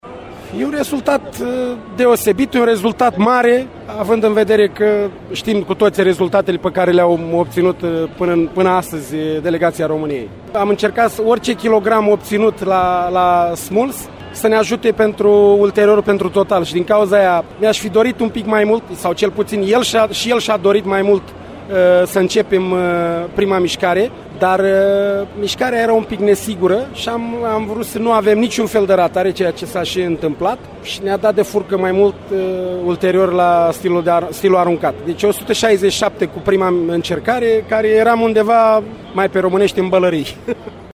Despre medalia obținută de halterofilul român a vorbit și Nicu Vlad, președintele FR Haltere: